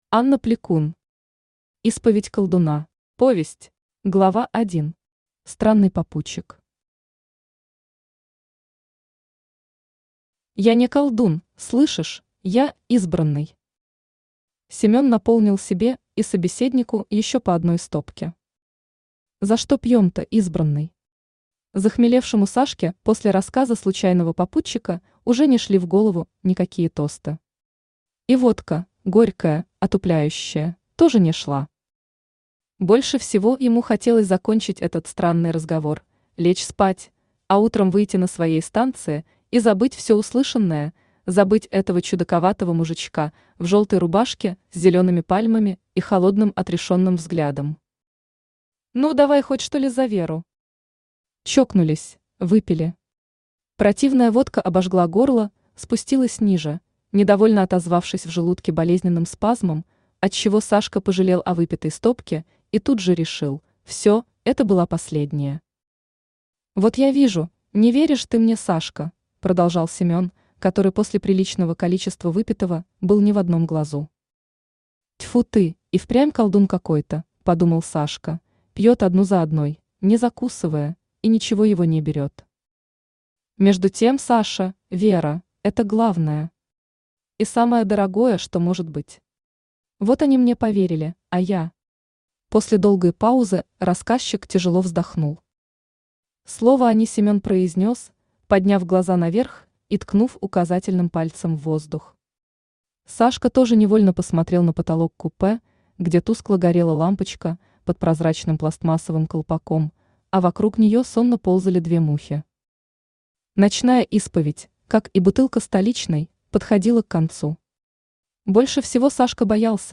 Аудиокнига Исповедь колдуна. Повесть | Библиотека аудиокниг
Aудиокнига Исповедь колдуна. Повесть Автор Анна Игоревна Плекун Читает аудиокнигу Авточтец ЛитРес.